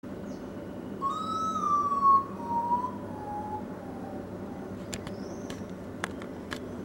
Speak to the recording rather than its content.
Location or protected area: Posadas Condition: Wild Certainty: Observed, Recorded vocal